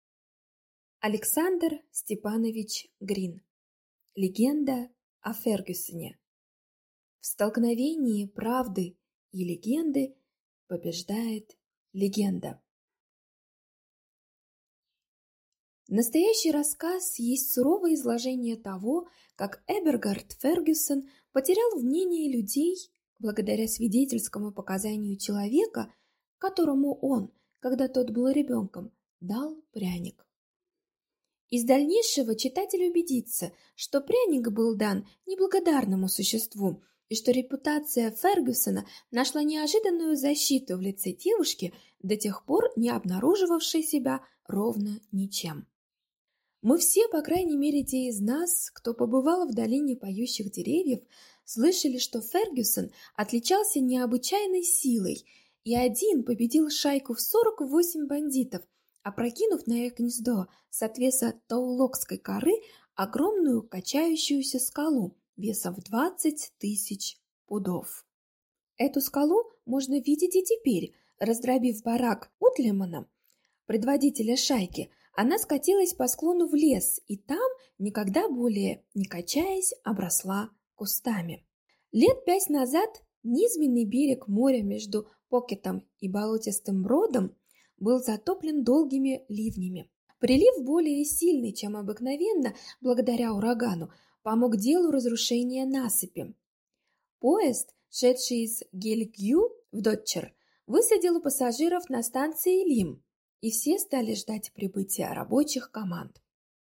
Аудиокнига Легенда о Фергюсоне | Библиотека аудиокниг